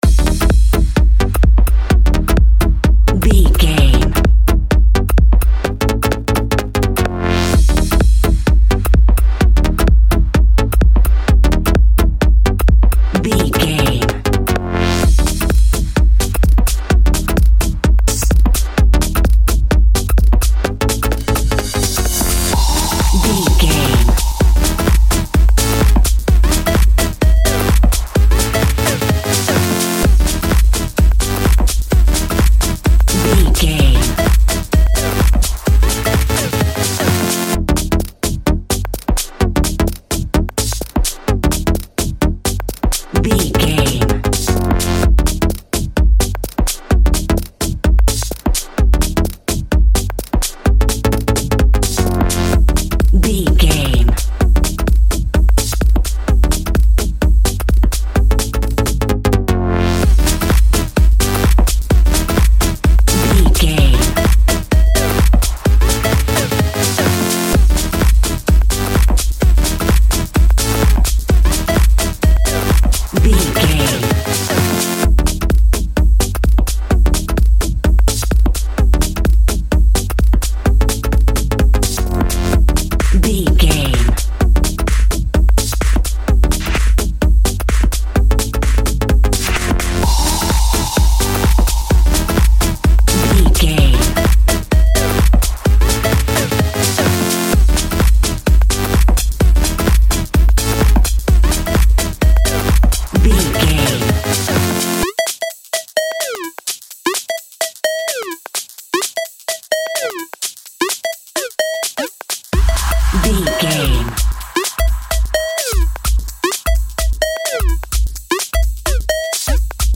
Deep Sub Dance Music.
Aeolian/Minor
Fast
groovy
uplifting
bouncy
hypnotic
drum machine
synthesiser
house
electro dance
techno
trance
instrumentals
synth leads
synth bass
upbeat